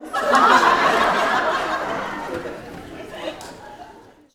Audience Laughing-06.wav